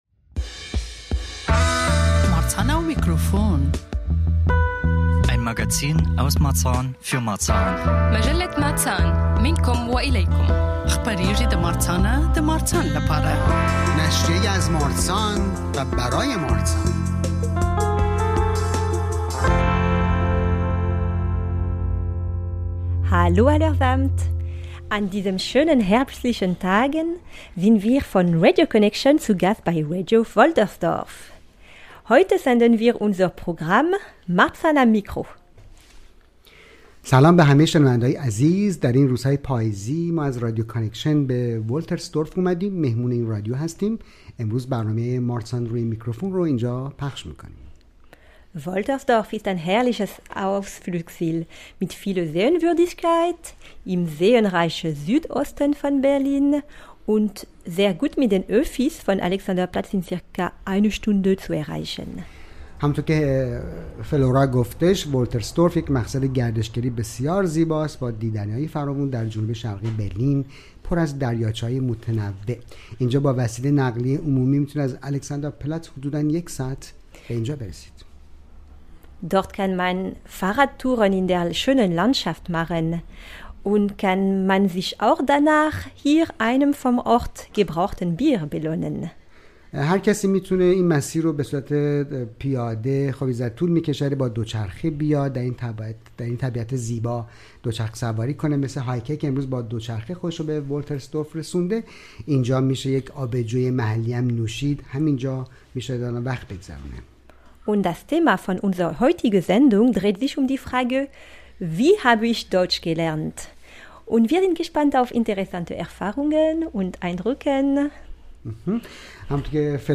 (deutsch/farsi) Wir sind zu Gast bei Radio Wolterdorf und senden live von 15-16 Uhr auf UKW 88,4 von dort. Hier findet gerade zum dritten Mal das Radio Industry – Festival für Kunst + Gegenwart statt, direkt an der Woltersdorfer Schleuse, einem neuen Hotspot für Kunst und Radio im Land Brandenburg....